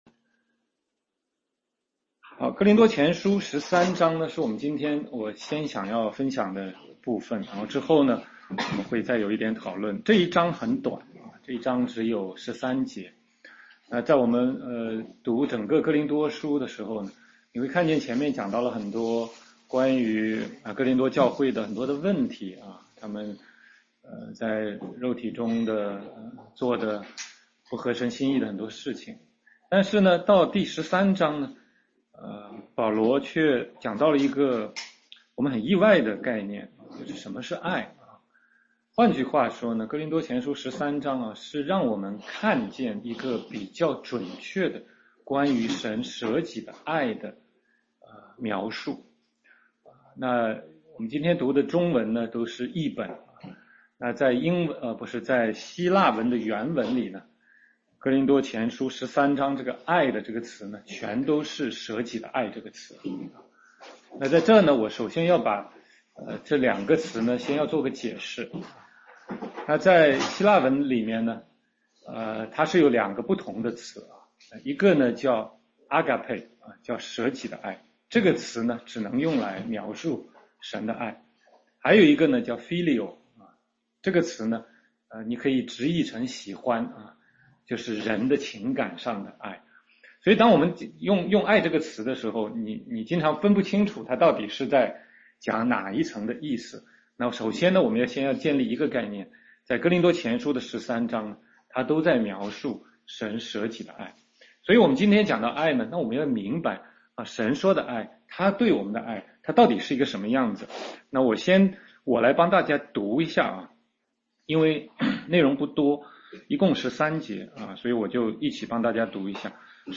16街讲道录音 - 哥林多前书13章—舍己的爱